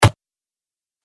دانلود آهنگ دعوا 33 از افکت صوتی انسان و موجودات زنده
جلوه های صوتی
دانلود صدای دعوا 33 از ساعد نیوز با لینک مستقیم و کیفیت بالا